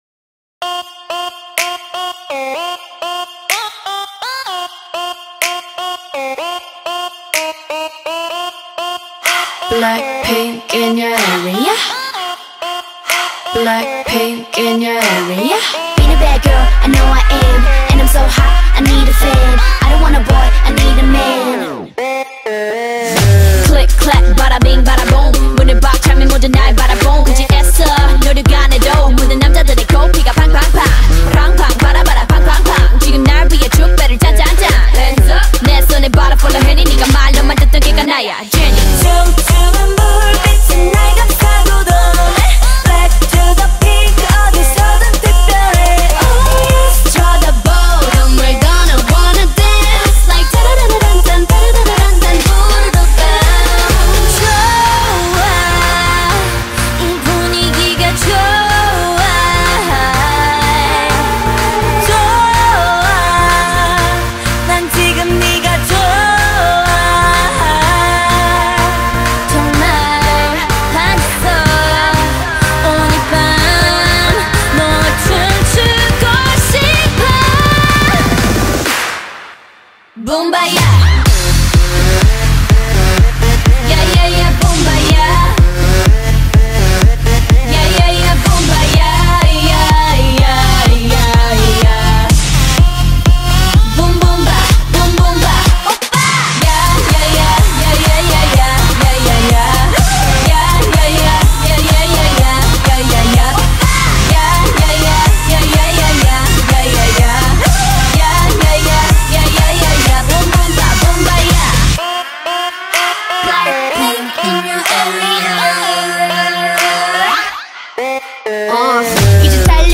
South Korean girl group